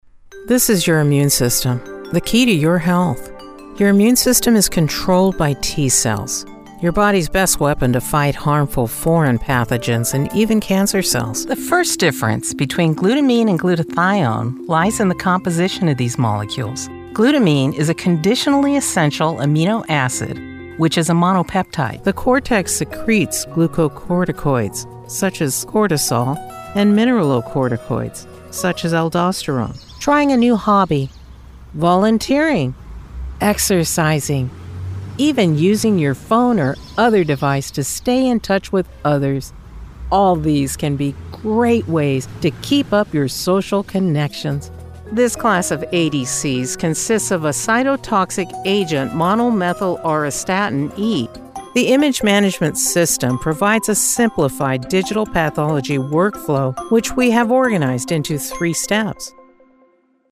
Medical Demo